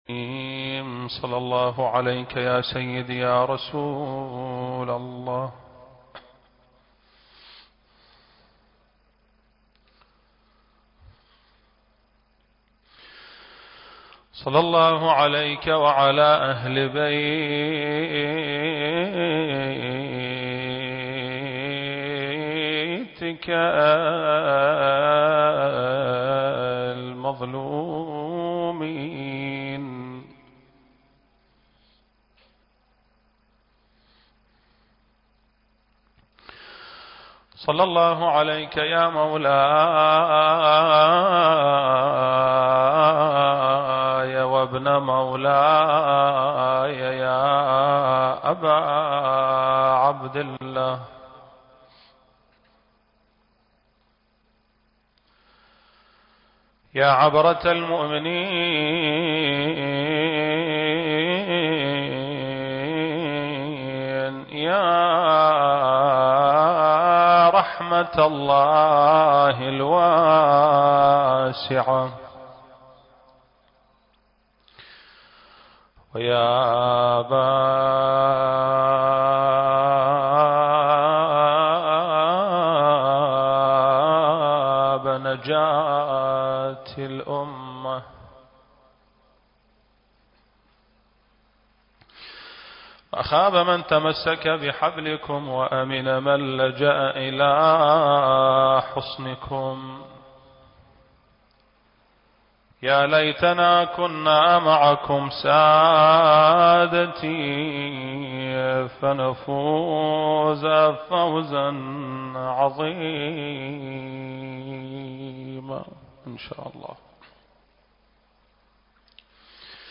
المكان: مسجد آل محمد (صلّى الله عليه وآله وسلم) - البصرة التاريخ: شهر رمضان المبارك - 1442 للهجرة